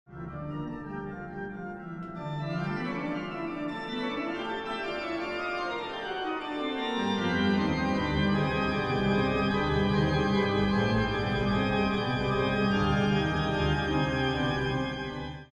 Builder: HinszType: Historic Dutch Baroque Organ
Location: Kampen, Netherlands